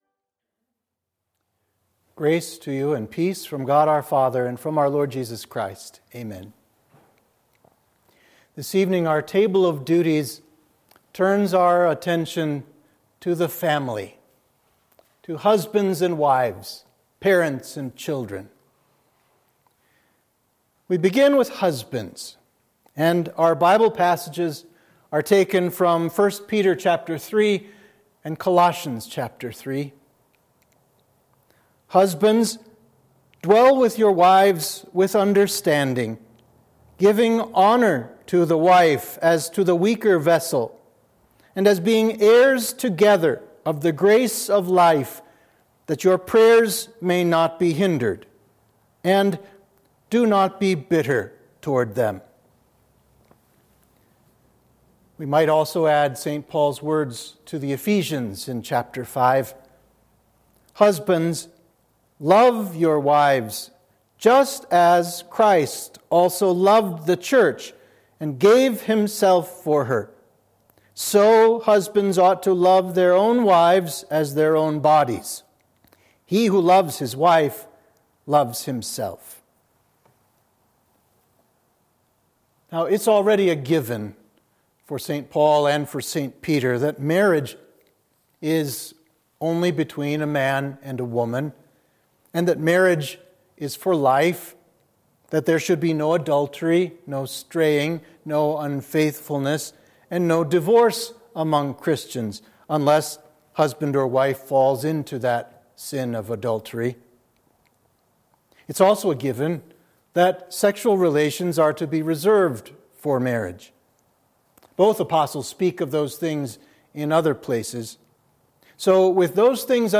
Sermon (audio)
Sermon for Midweek of Oculi – Lent 3